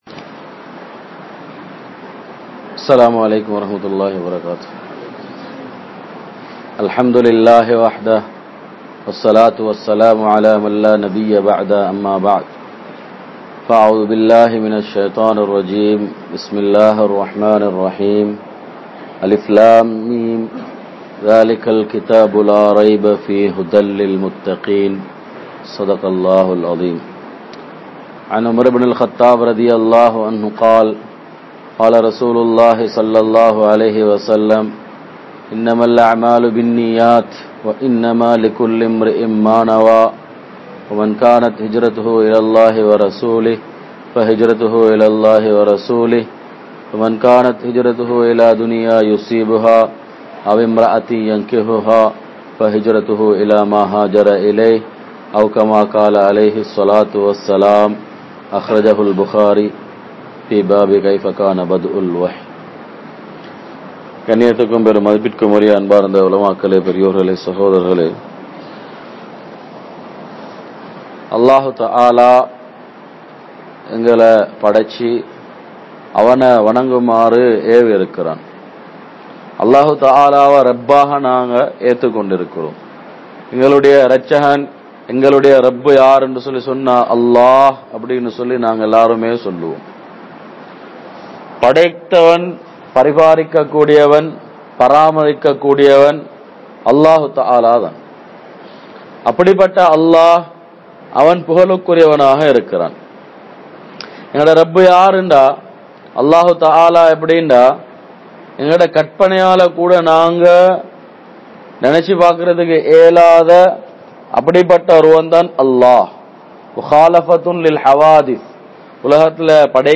Athisayamaana Manithar Nabi(SAW) (அதிசயமான மனிதர் நபி(ஸல்)) | Audio Bayans | All Ceylon Muslim Youth Community | Addalaichenai